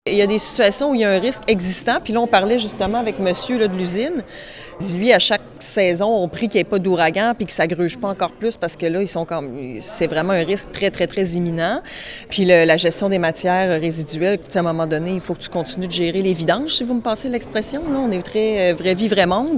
Elle explique la priorisation des secteurs ciblés par le bureau de projets en érosion et submersion côtières:
Une conférence de presse était organisée à la salle de la mairie par le ministère des Affaires municipales et de l’Habitation pour annoncer les subventions.